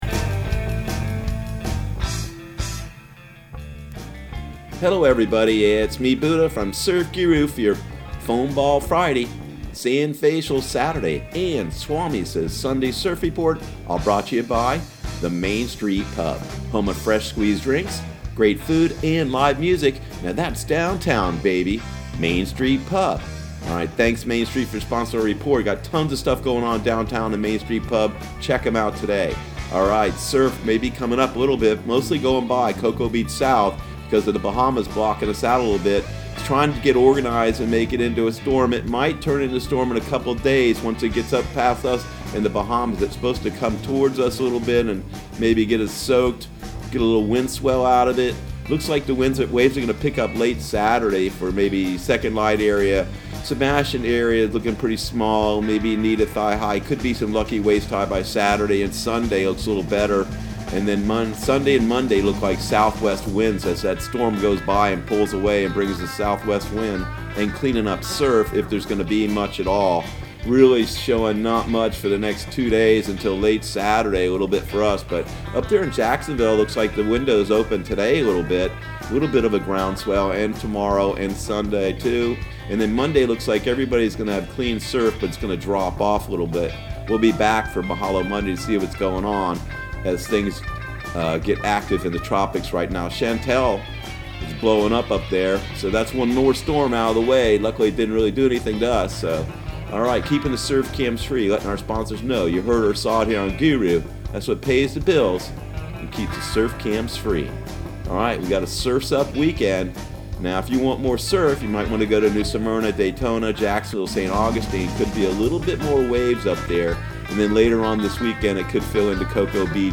Surf Guru Surf Report and Forecast 08/23/2019 Audio surf report and surf forecast on August 23 for Central Florida and the Southeast.